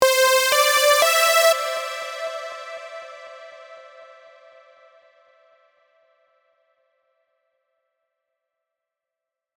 まさに SuperSaw といった音です。